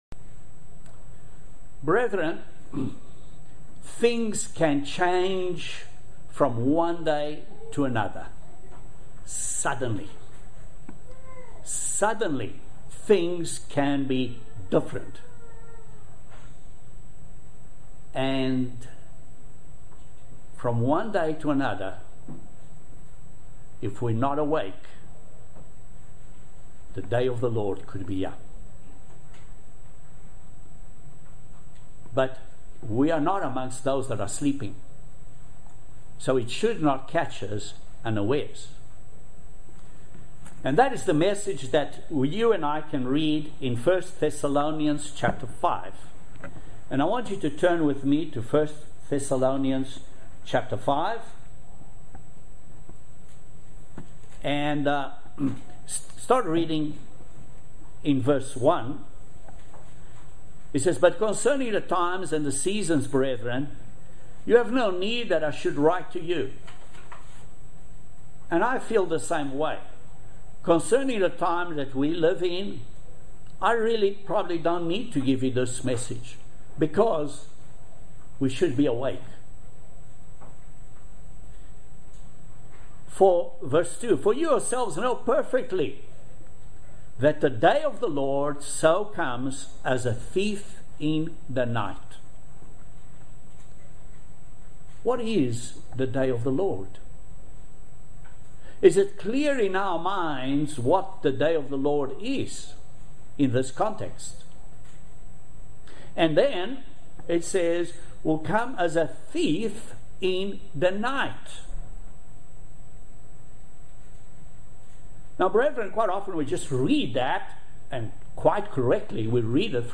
Join us in watching this excellent video sermon on building up to the day of the Lord. Are we spiritually awake or asleep? Are we supposed to be sons of the light and sons of the day?